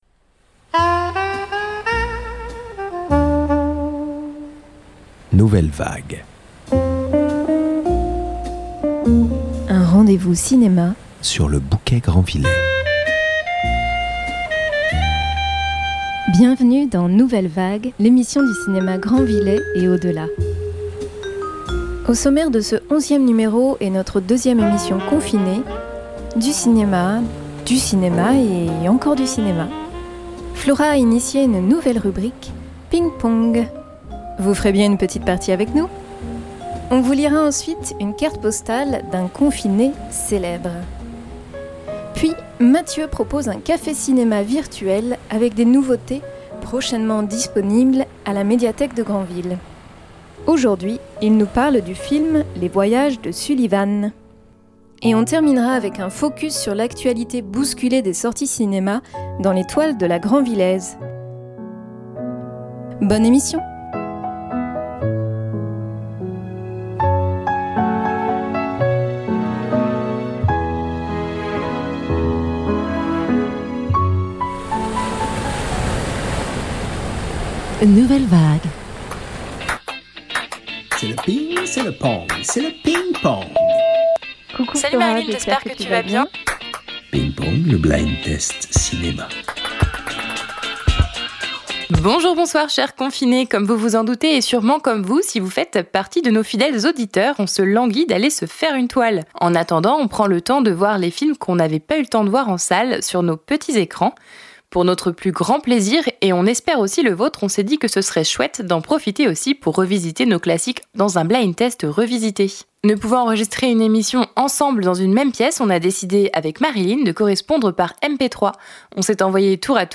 Les extraits de musiques de films qui ponctuent l’émission :